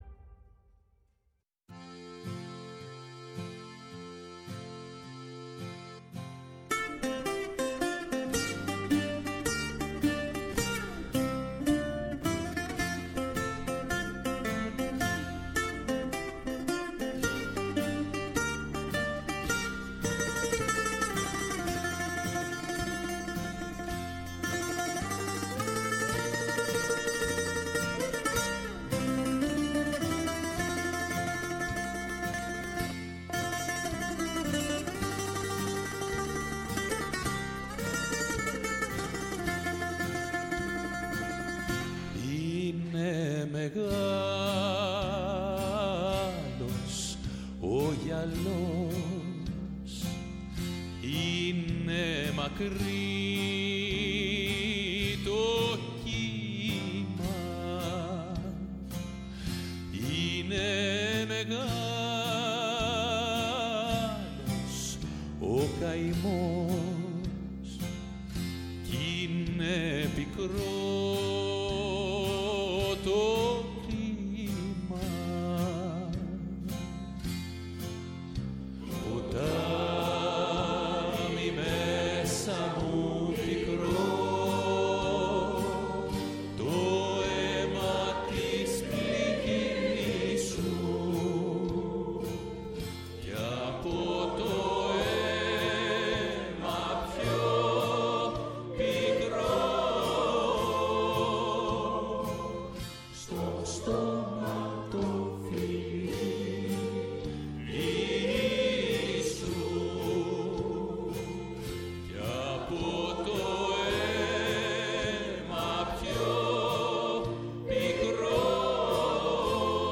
Στο στούντιο της “Φωνής της Ελλάδας”